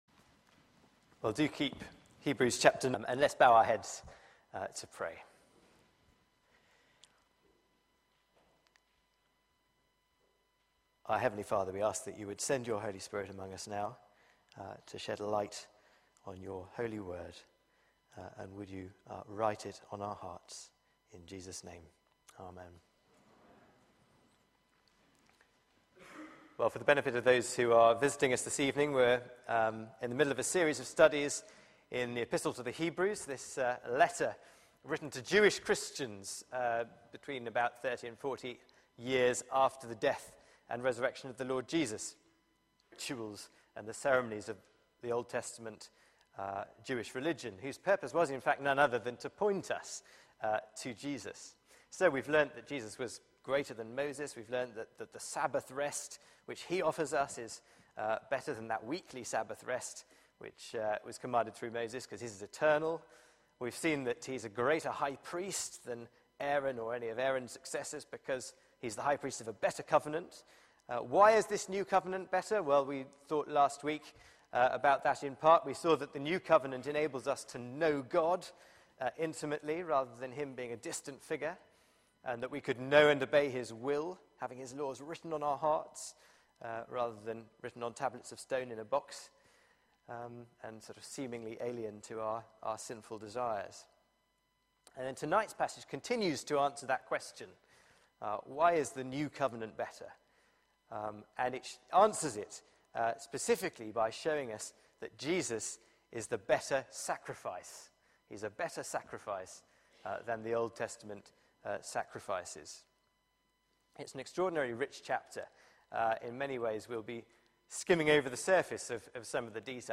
Media for 6:30pm Service on Sun 17th Nov 2013 18:30 Speaker
Series: Jesus is better Theme: A sacrifice that works Sermon